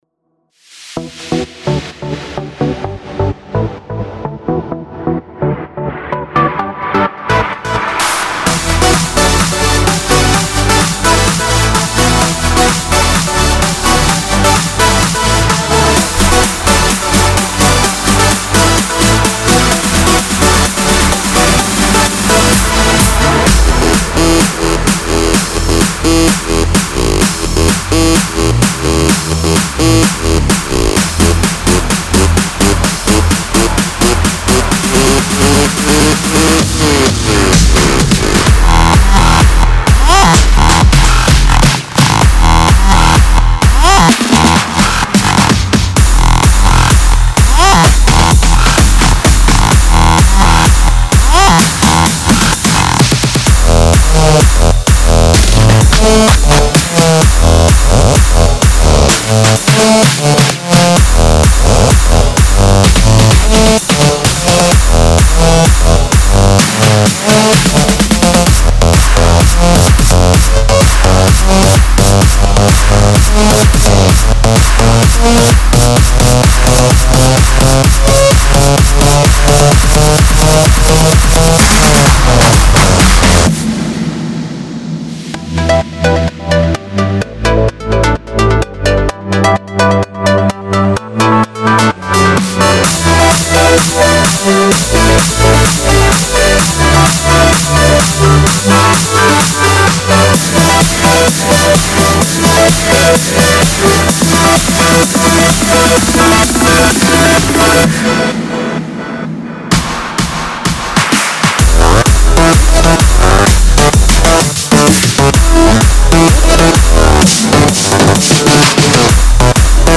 • 50 1 Bar Triplet Fills
• 30 4 Bar Drums Only Build Ups
• 30 4 Bar Synth Only Build Ups